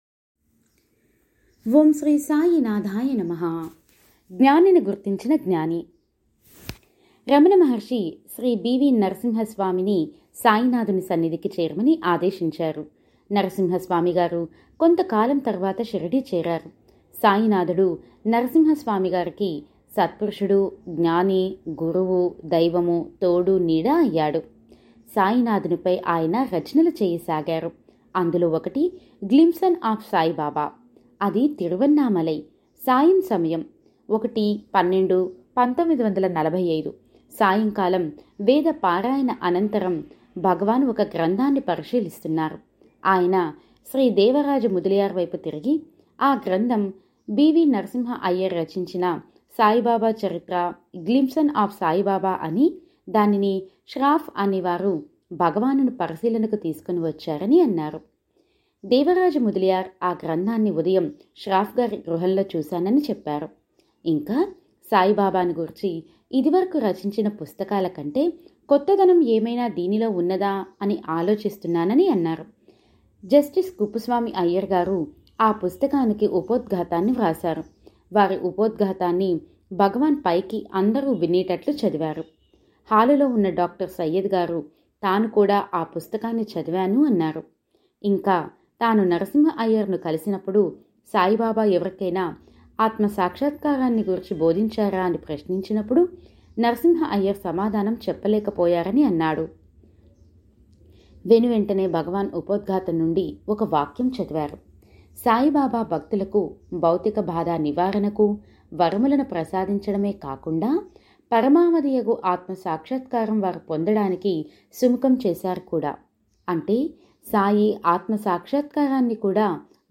Voice Support By: